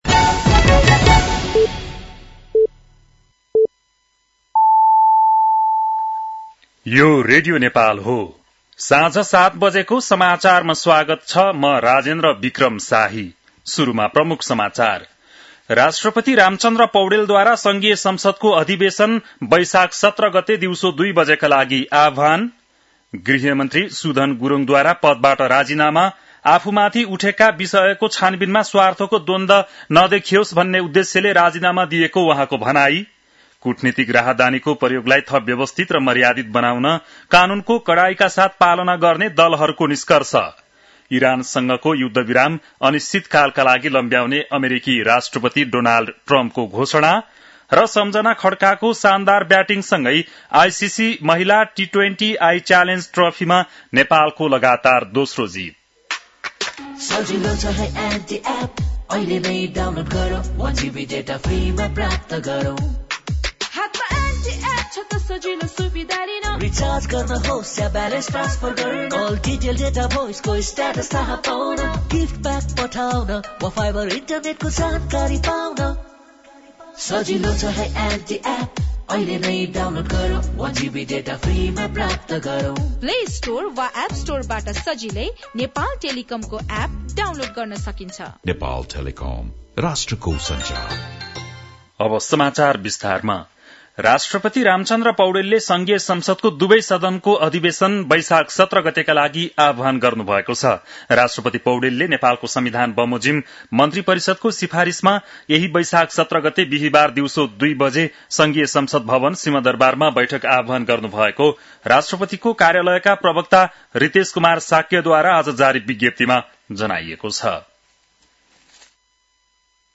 बेलुकी ७ बजेको नेपाली समाचार : ९ वैशाख , २०८३